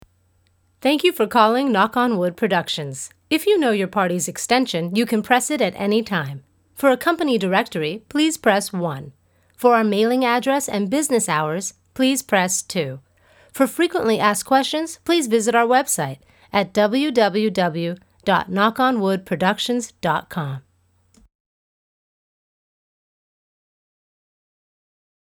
Female
Yng Adult (18-29), Adult (30-50)
Phone Greetings / On Hold
Answering Service. Greeting.